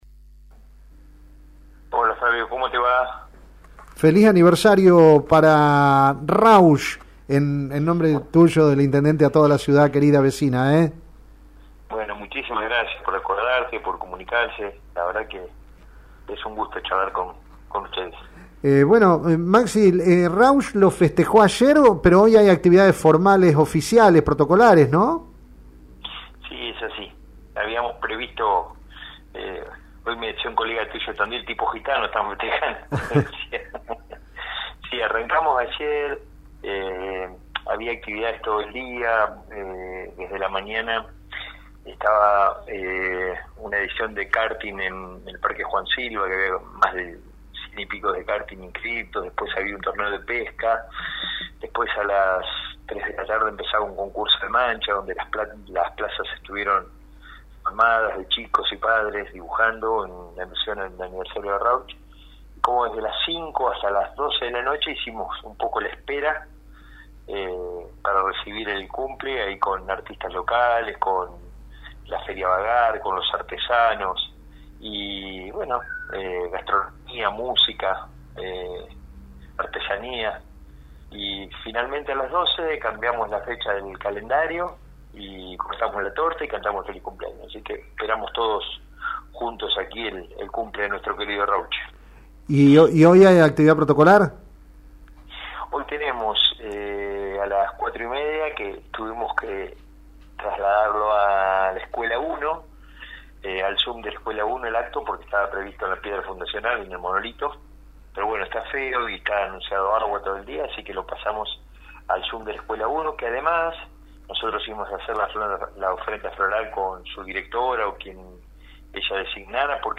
En el dia del aniversario 154 de la vecina localidad de Rauch, saludamos al intendente municipal, Maximiliano Suescun y en su nombre a todos quienes habitan esa ciudad.